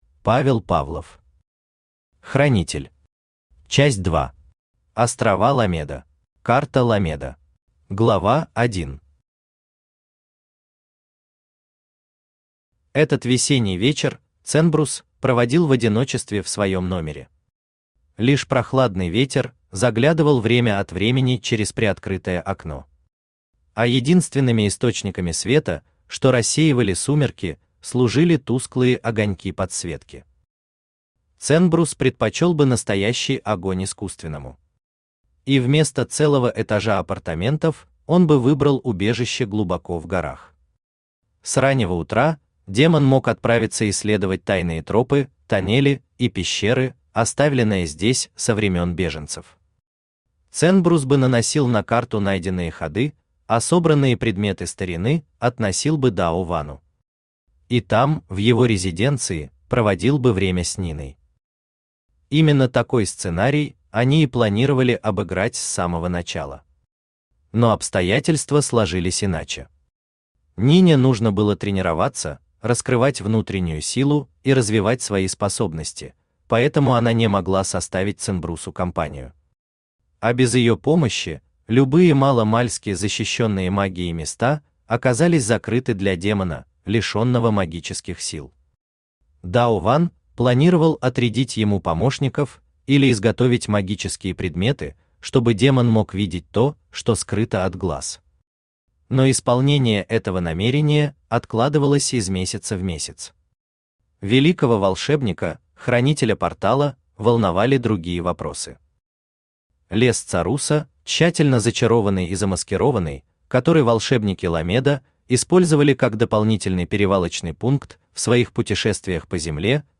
Аудиокнига Хранитель. Часть 2. Острова Ламеда | Библиотека аудиокниг
Острова Ламеда Автор Павел Павлов Читает аудиокнигу Авточтец ЛитРес.